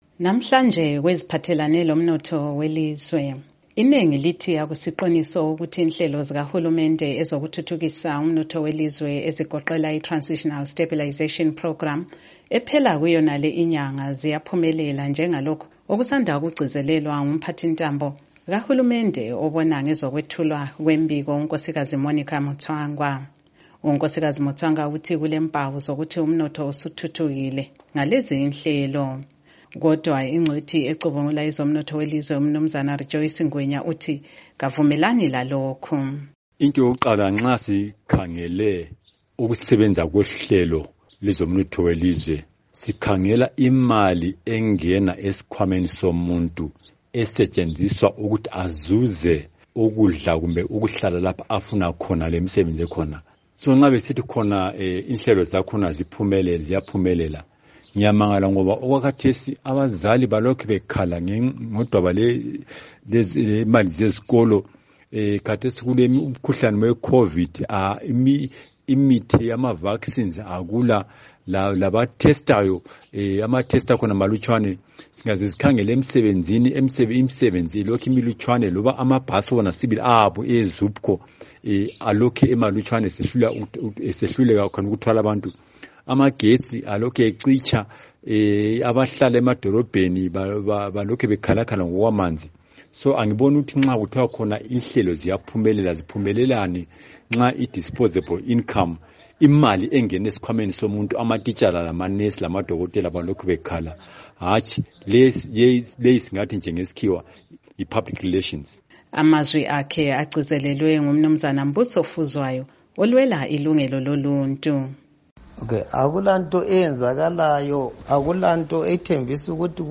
Sixoxa lengcwethi ezicubungula ezombusazwe, abalwela ilungelo loluntu, kanye lezakhamizi, abaveza imbono ehlukeneyo ngaloludaba.